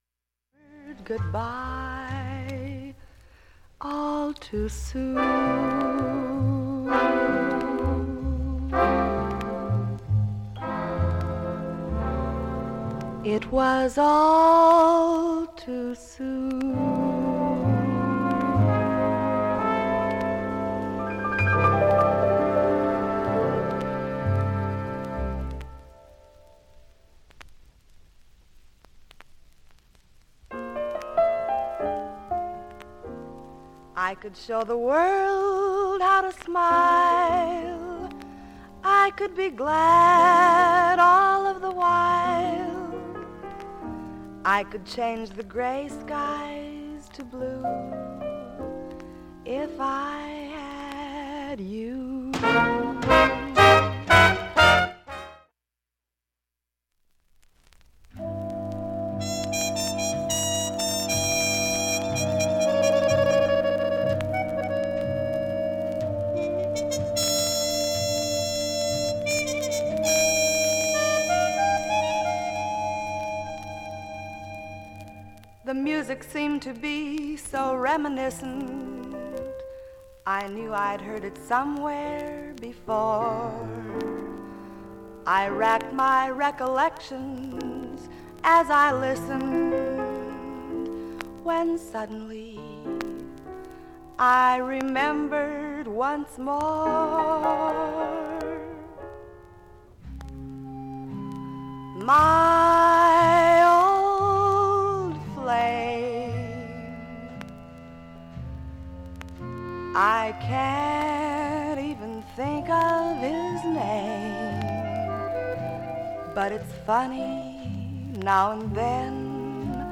など出ますが基本クリアな音質
６回までのかすかなプツが８箇所
３回までのかすかなプツが４箇所
単発のかすかなプツが６箇所
深溝 MONO